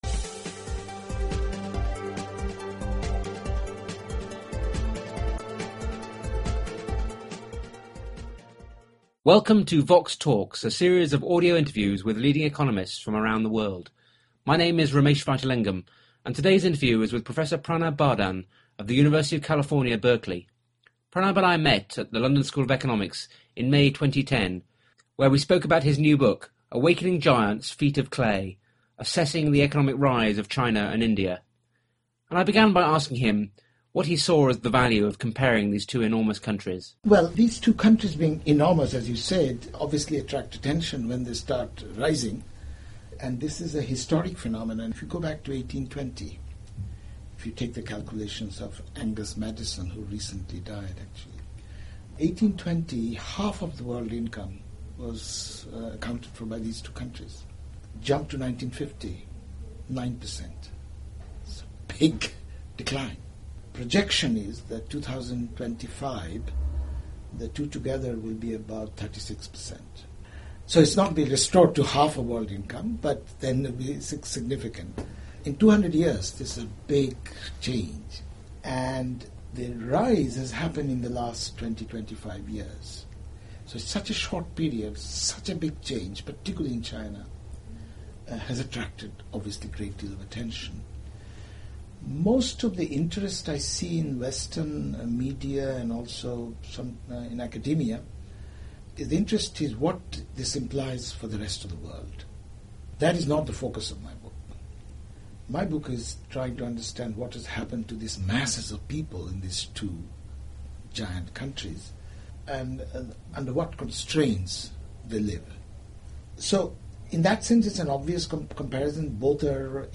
He argues that significant poverty reduction in both countries is mainly due to domestic factors – not global integration, as most would believe. The interview was recorded at the London School of Economics in May 2010.